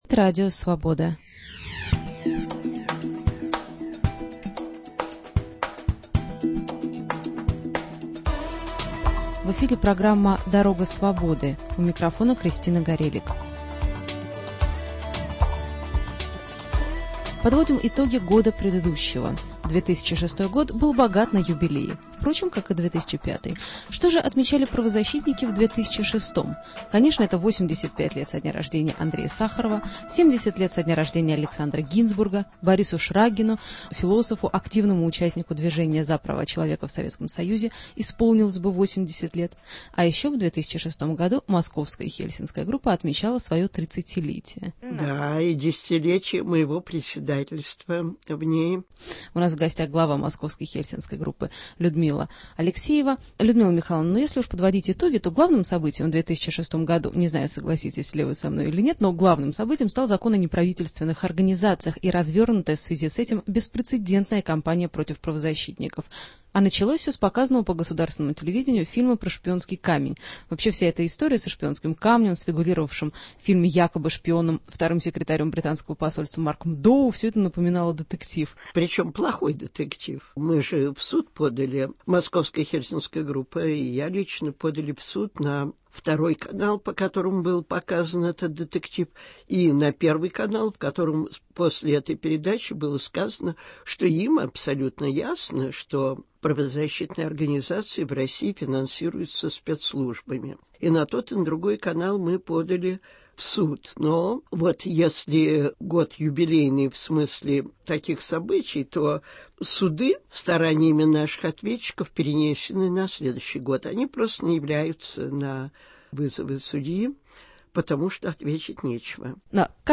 Преследования правозащитников в 2006 году. Людмила Алексеева о законе об НПО. Репортажи из регионов о гонениях на правозащитников.